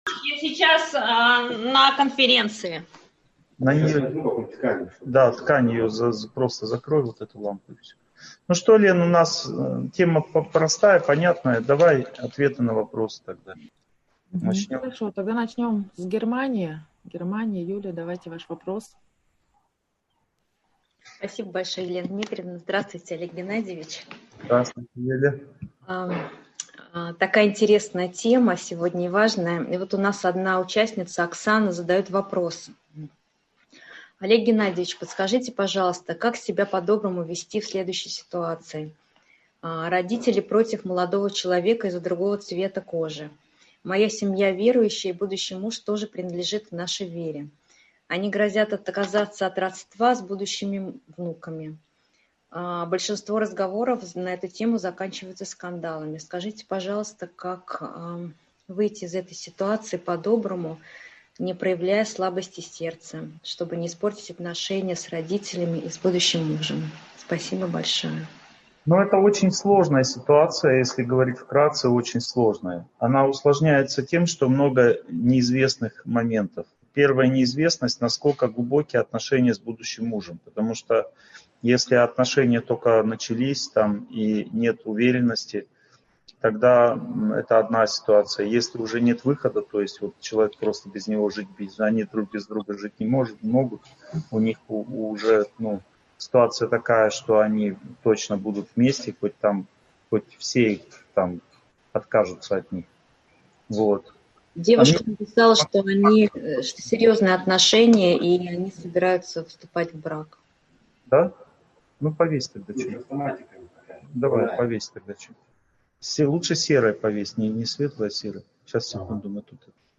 Слабость сердца и доброта. В чем различие? (онлайн-семинар, 2020)